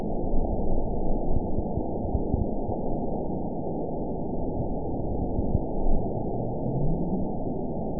event 920355 date 03/18/24 time 03:10:05 GMT (1 month, 1 week ago) score 9.62 location TSS-AB03 detected by nrw target species NRW annotations +NRW Spectrogram: Frequency (kHz) vs. Time (s) audio not available .wav